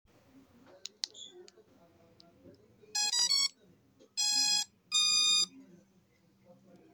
F24 DRONE'S POWER UP SOUND